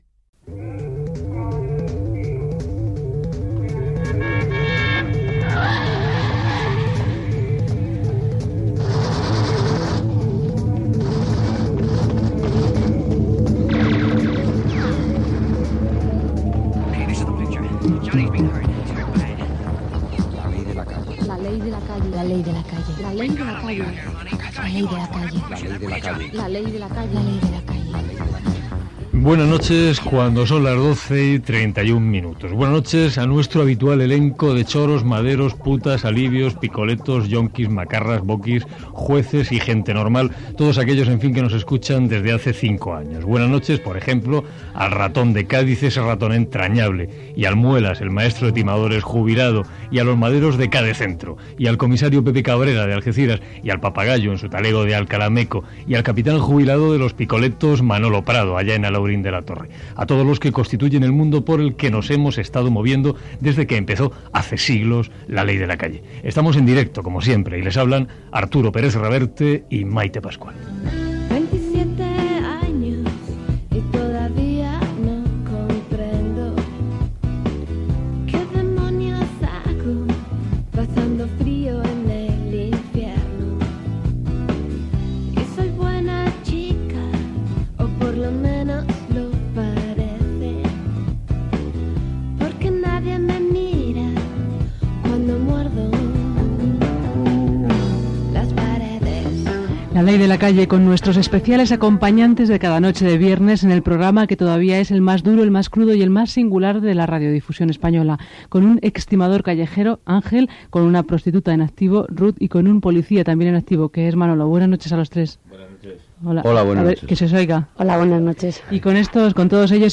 Sintonia, salutació inicial de l'última edició del programa, telèfons de participació i comentari sobre el programa.Tema musical i diàleg amb els col·laboradors del programa i trucada telefònica d'una oïdora
Info-entreteniment